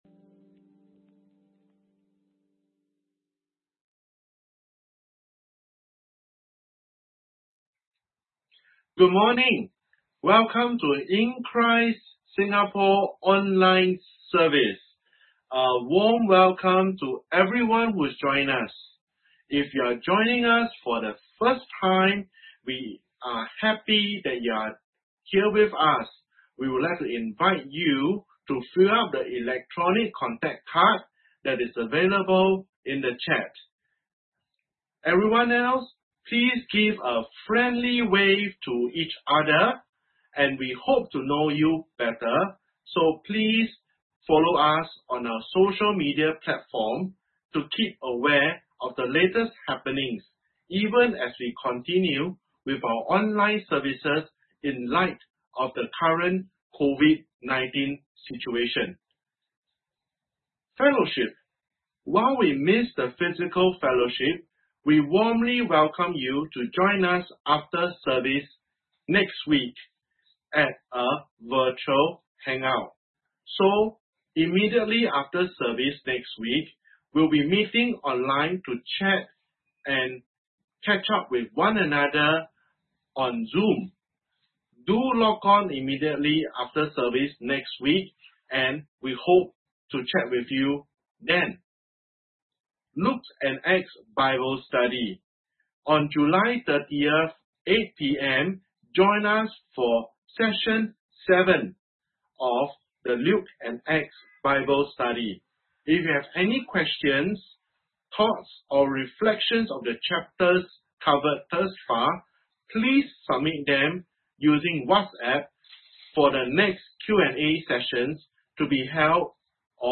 Sermon
10am service